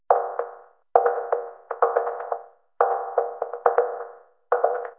notify.mp3